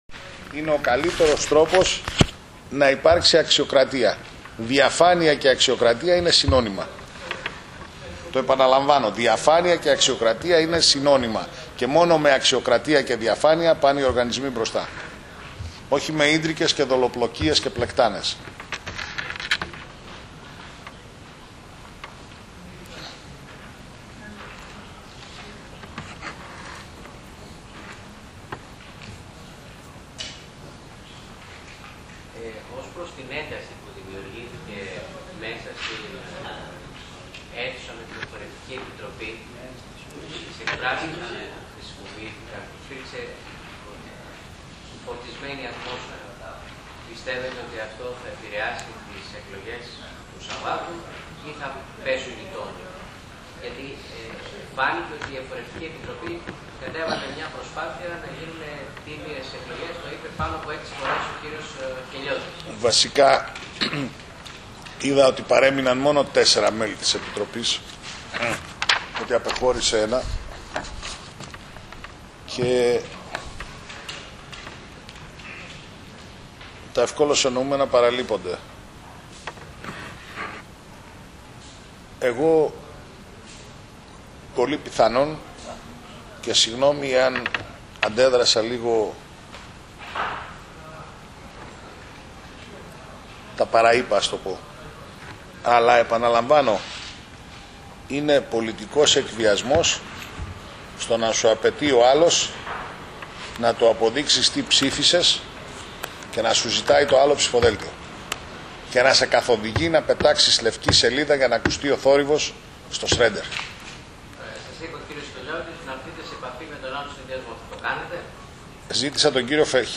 ΣΥΝΕΝΤΕΥΞΗ ΤΥΠΟΥ
Η συνέντευξη Τύπου δόθηκε μέσα σε φορτισμένο κλίμα και τεταμένη ατμόσφαιρα